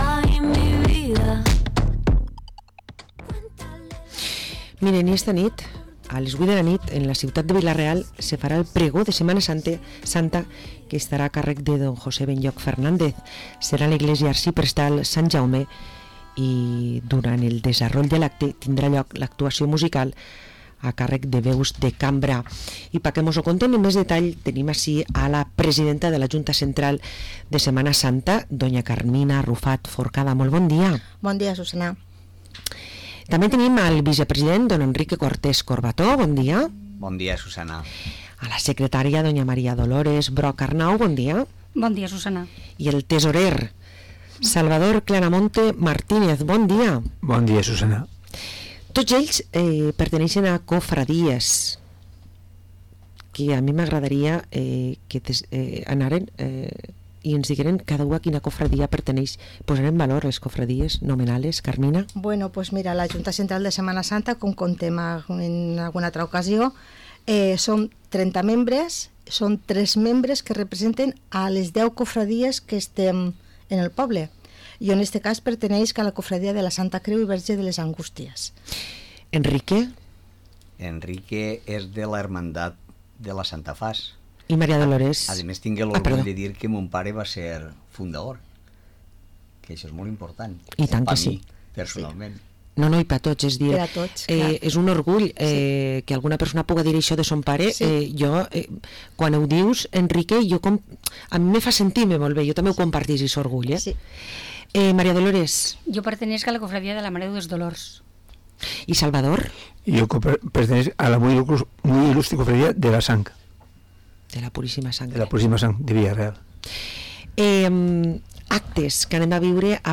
Comencen els actes de Setmana Santa a Vila-real, parlem amb membres de la Junta Central